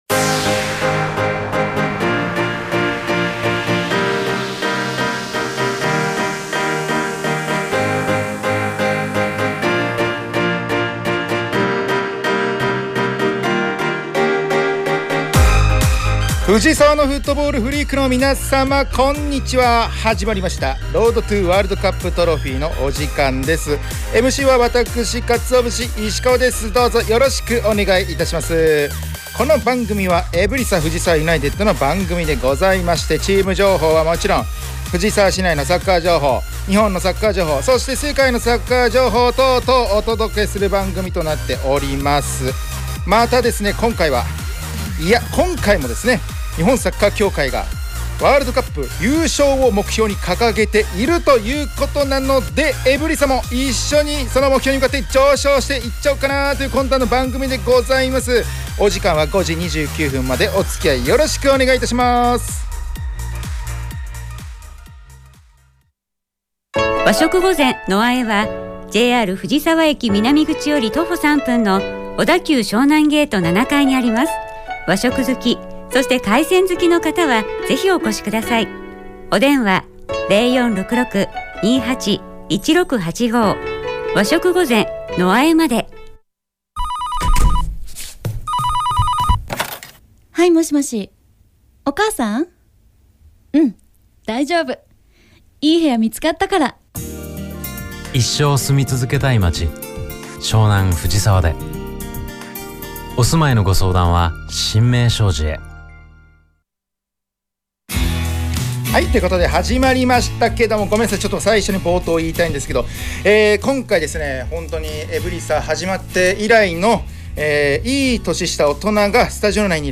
エブリサ藤沢ユナイテッドが提供する藤沢サッカー専門ラジオ番組『Road to WC Trophy』の第2期の第85回放送が11月14日(金)17時に行われました☆